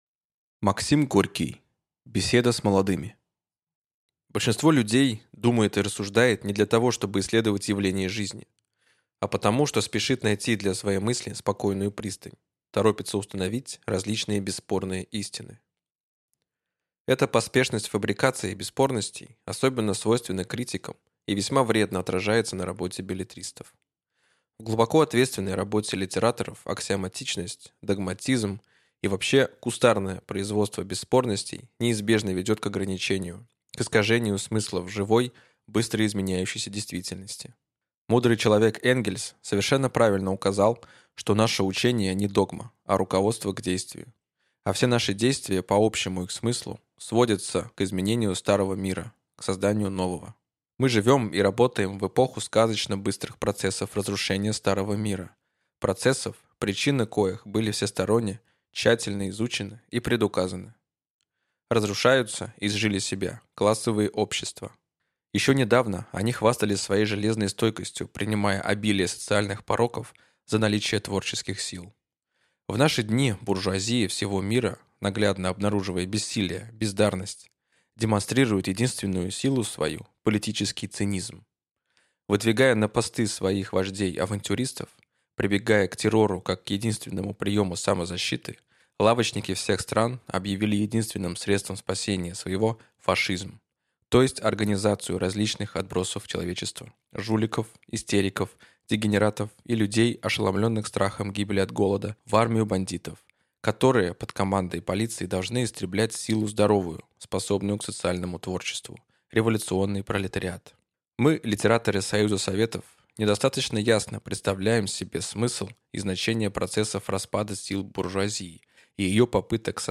Аудиокнига Беседа с молодыми | Библиотека аудиокниг